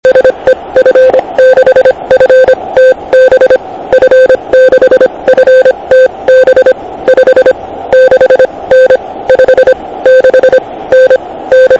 Ovšem poslech v místě, kde není široko daleko žádné elektrické vedení - to je zážitek. A jak jsem některé z Vás slyšel?